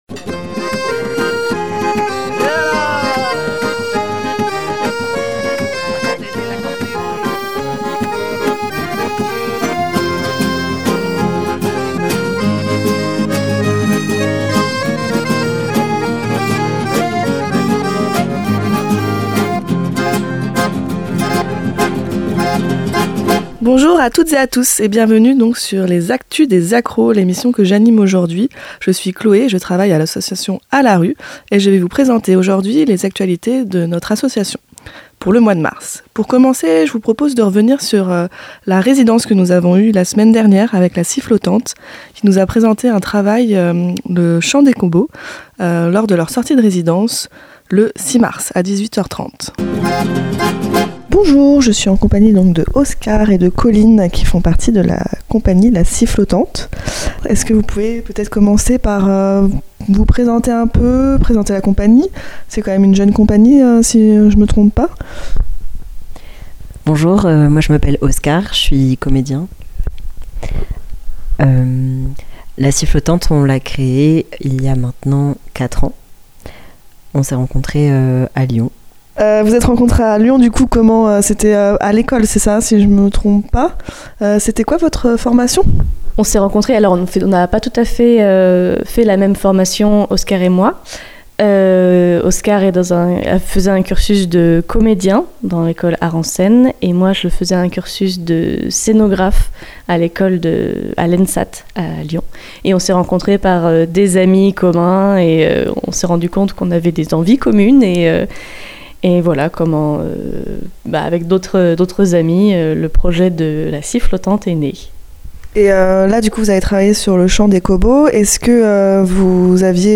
Le podcast de l'émission du mardi 11 mars 2025